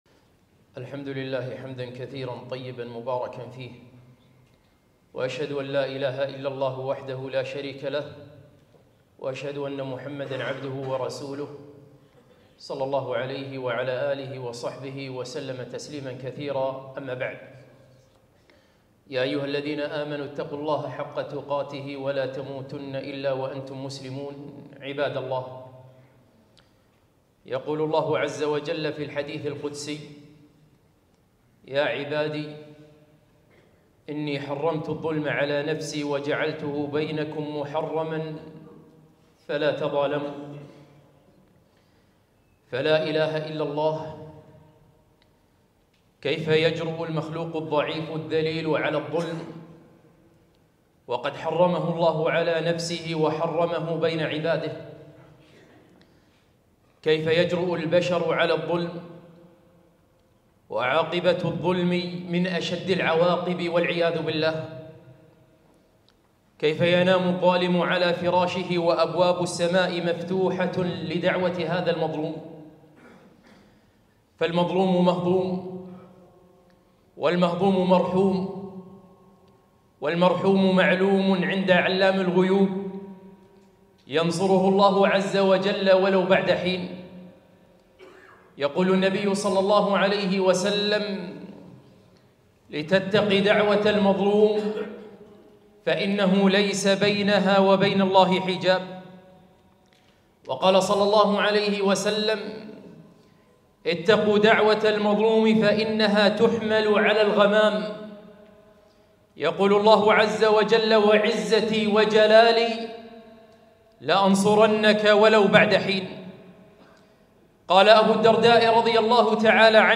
خطبة - اتقوا الظلم